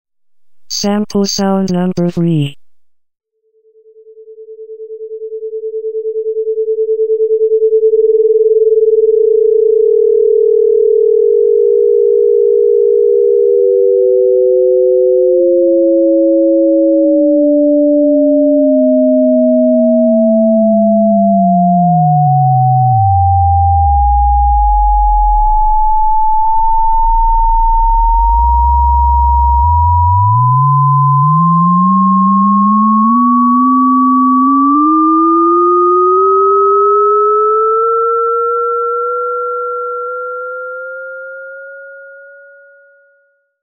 ●リング変調（
この例では、被変調波440Hzに対して、対等な変調波を数Hzから1000Hz まで変化させたので、両者の「差音」が、途中で両者の周波数が一致する あたりで相殺されることなども判る。